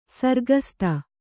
♪ sargasta